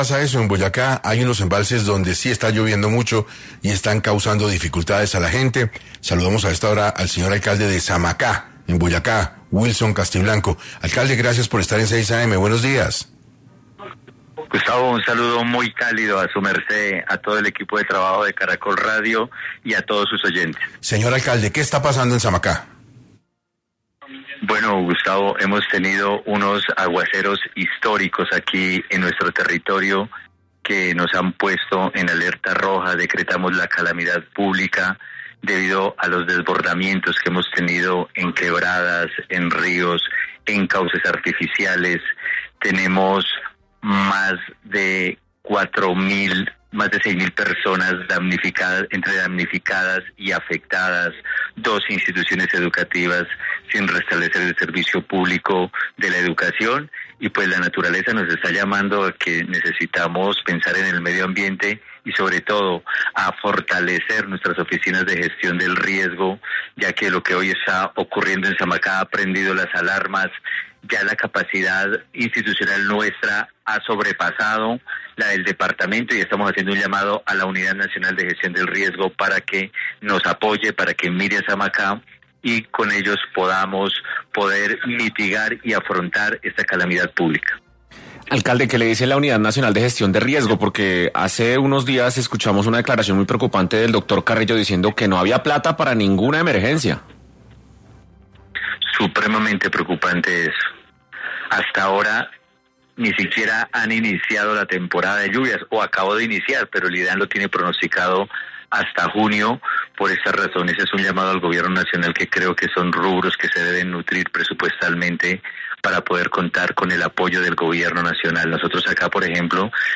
En 6AM de Caracol Radio habló Wilson Castiblanco, Alcalde de Samacá en Boyacá, quien comunicó sobre los fuertes aguaceros en Samacá que han causado desboramientos en los embalses